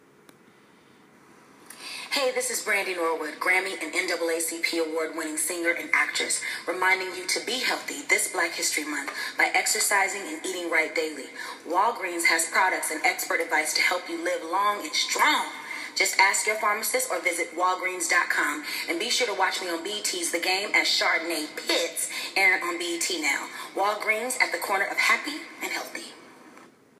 Brandy Walgreens Commercial:
Talent Procurement: Brand Maverick suggested a national in-store radio, celebrity influencer based campaign to help both drive traffic into store as deliver a message of health and wellness.
After devising a script that hit all of Walgreens health & wellness objectives, GRAMMY Award wining singer & NAACP Award winning actress BRANDY was brought in to record a message that served as creative way for Walgreens to stay committed to the promise of helping people get, stay and live well.
Brandy-Walgreens-Commercial.m4a